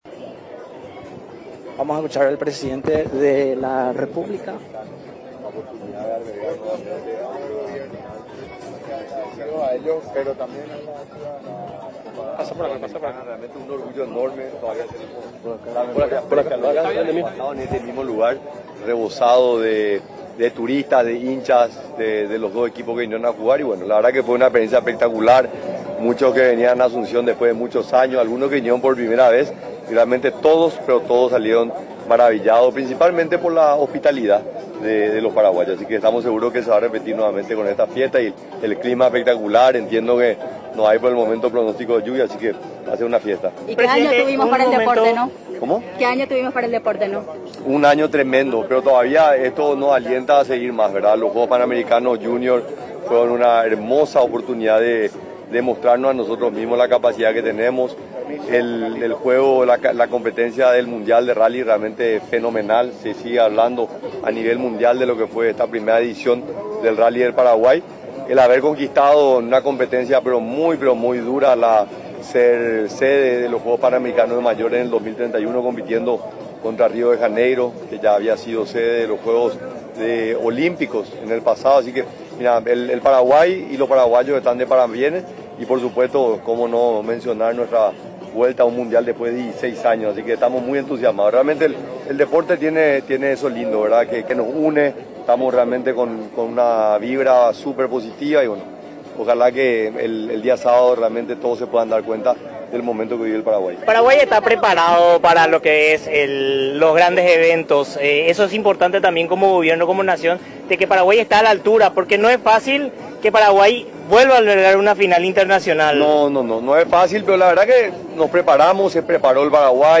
El presidente de la República, Santiago Peña refirió este jueves, que la Final Única de la Copa Sudamericana que se desarrollará en Asunción, este sábado en el estadio de los Defensores del Chaco, será una fiesta.